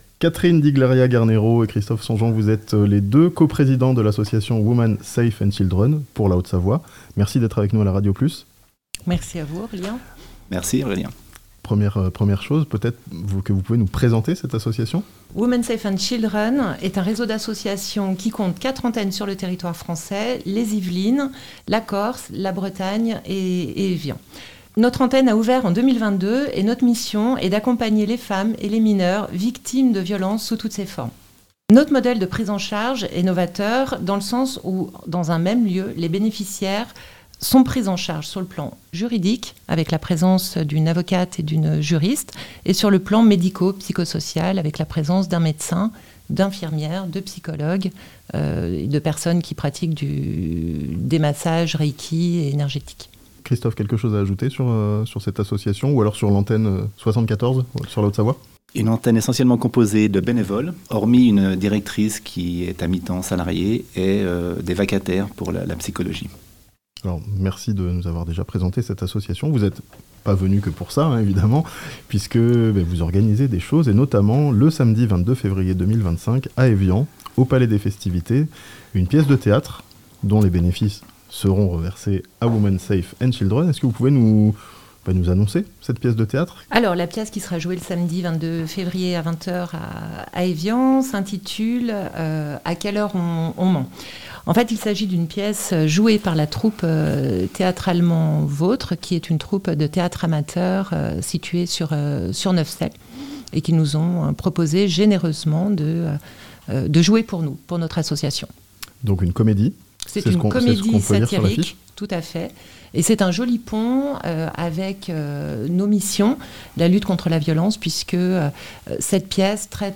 Théâtre à Evian: une comédie au profit de l'association Women Safe and Children (interview)